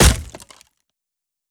Hit_Wood 04.wav